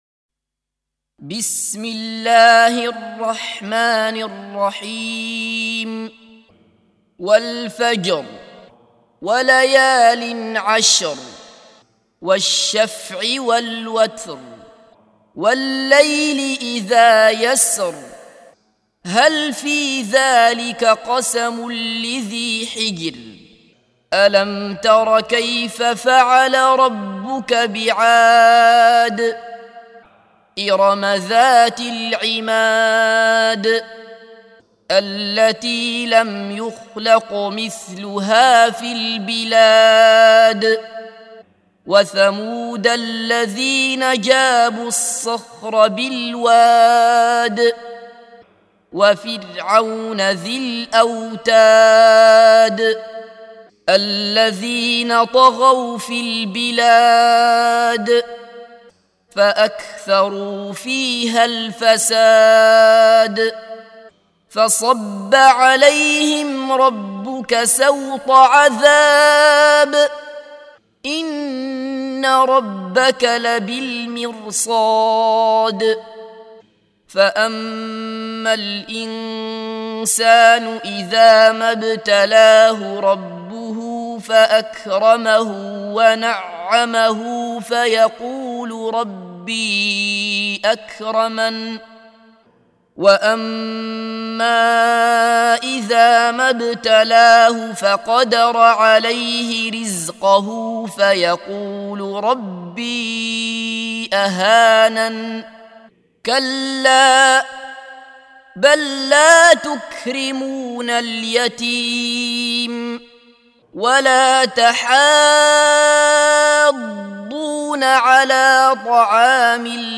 سُورَةُ الفَجۡرِ بصوت الشيخ عبدالله بصفر